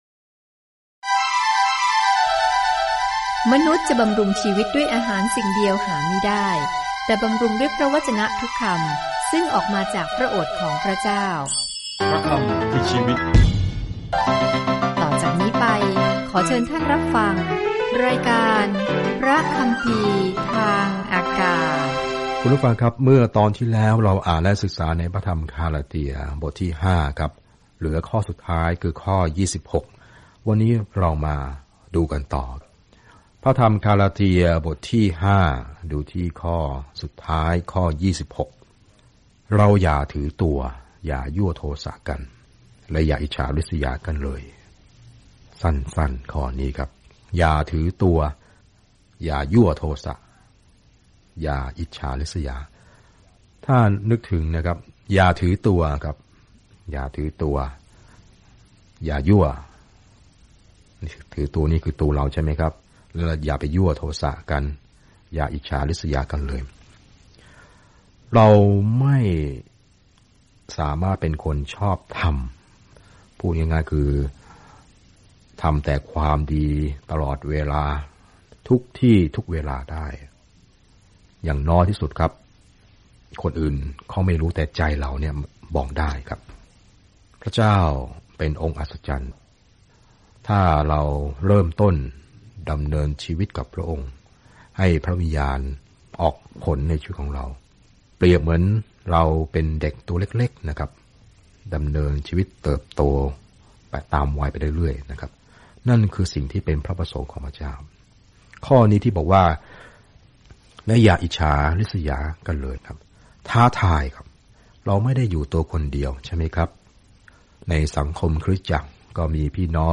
เดินทางทุกวันผ่านกาลาเทียในขณะที่คุณฟังการศึกษาด้วยเสียงและอ่านข้อที่เลือกจากพระวจนะของพระเจ้า